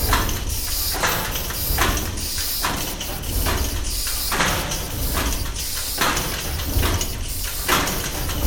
machine-loop-01.ogg